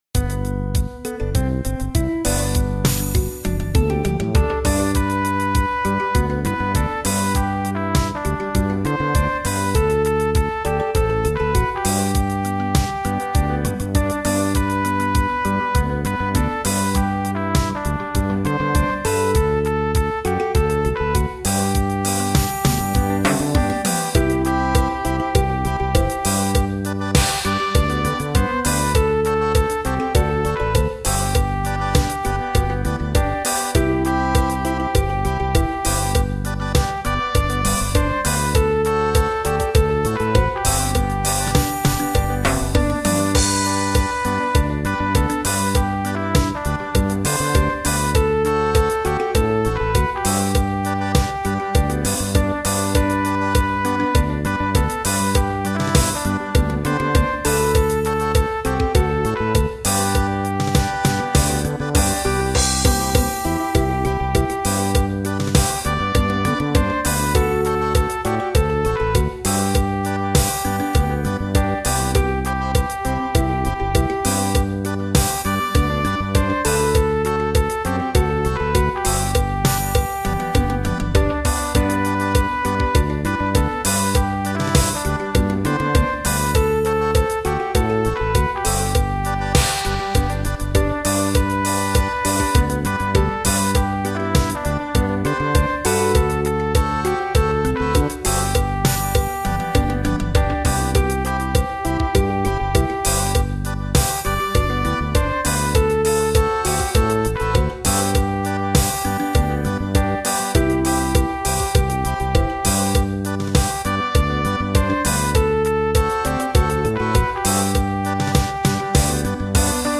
Siyahamba aka We Are Marching (AOV 2/113) | Church Music
This is a traditional African melody that we have sung with the original text.